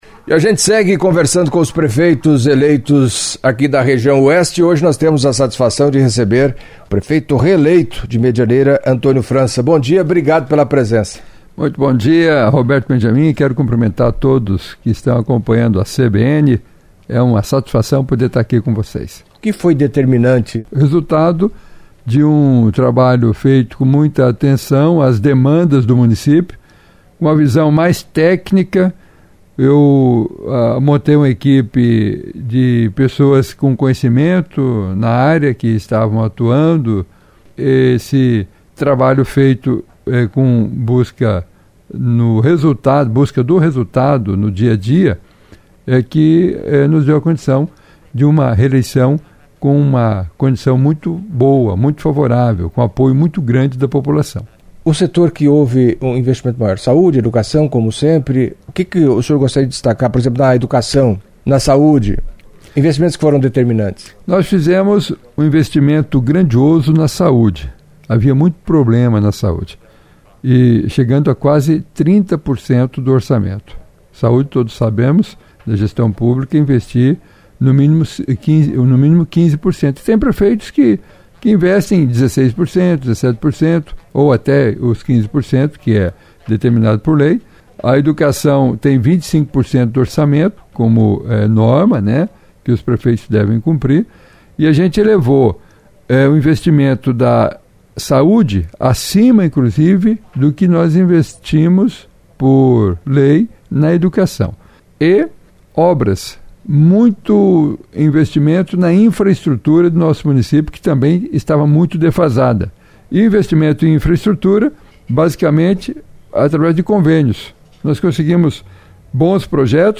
Na série de entrevistas com prefeitos eleitos e reeleitos da região Oeste do Paraná, Antonio França, reeleito em Medianeira com 72,81%, foi o entrevistado desta sexta-feira (22) na CBN Cascavel. O prefeito França (PSD) destacou os avanços, investimentos na Saúde, Educação, inúmeras obras na cidade e no inteirior e mostrou-se muito otimista para o segundo mandato.